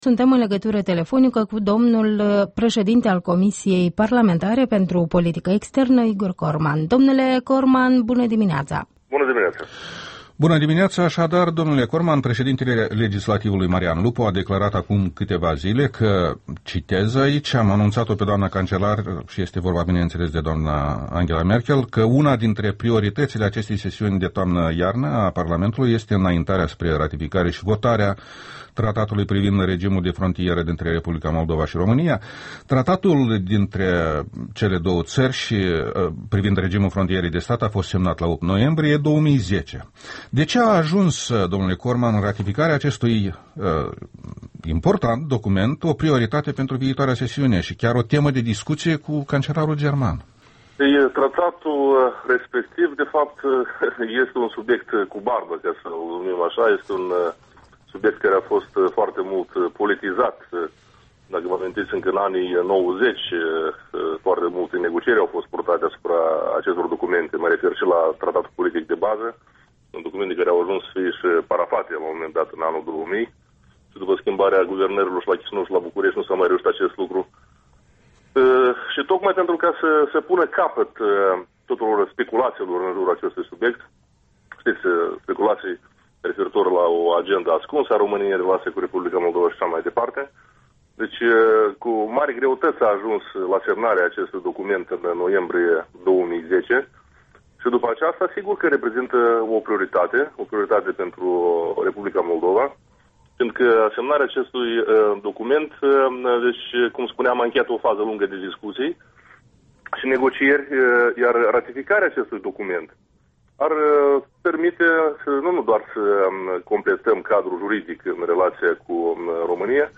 Tratatul de frontieră cu România în dezbatere: un interviu cu Igor Corman